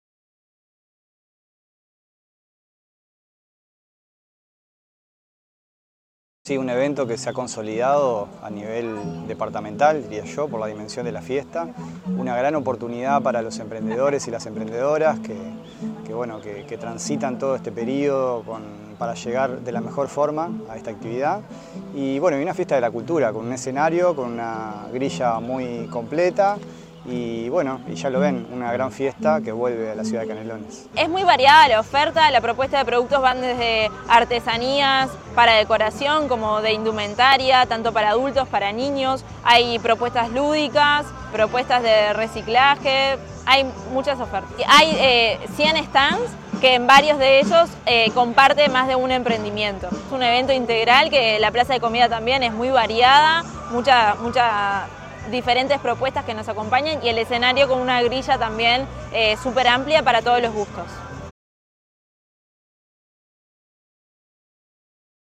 sergio_machin_director_general_de_cultura_3.mp3